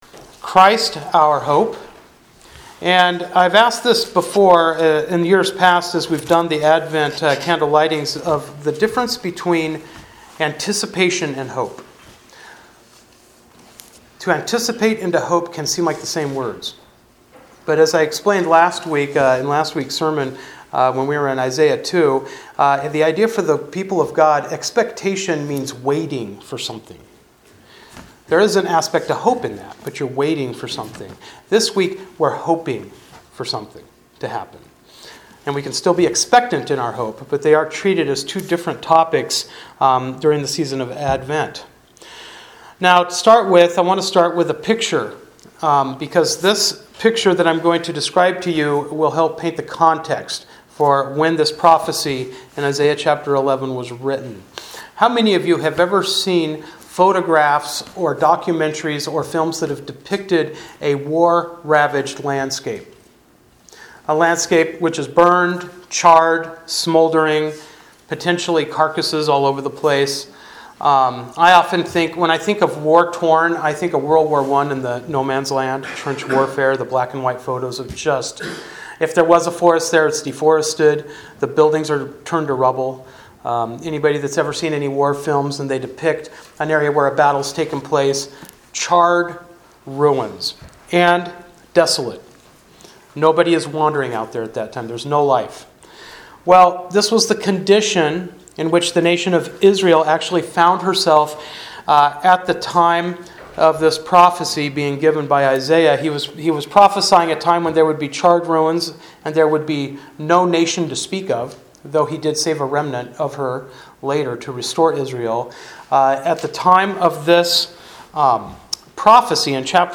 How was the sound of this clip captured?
Service Type: Sunday Morning Worship